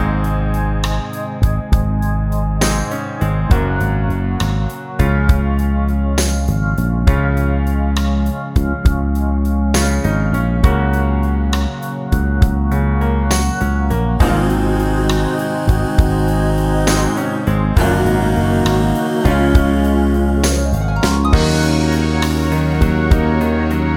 No Sax Pop (1990s) 3:55 Buy £1.50